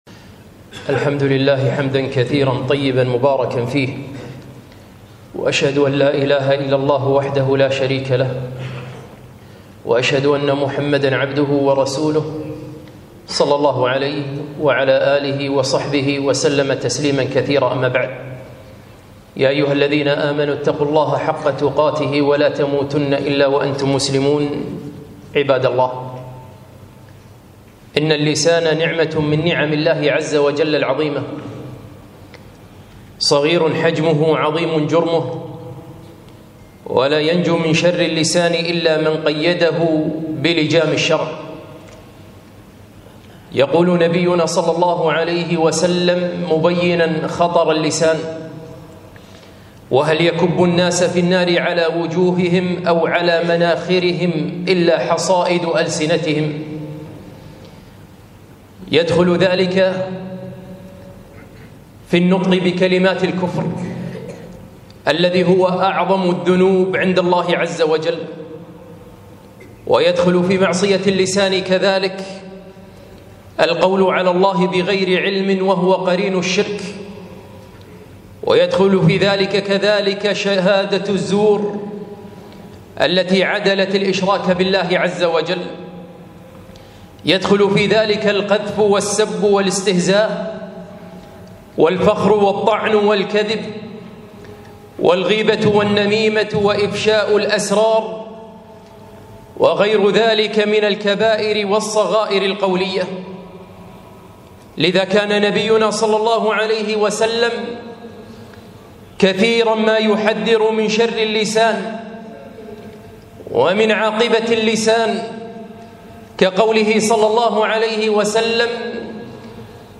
خطبة - حفظ اللسان - دروس الكويت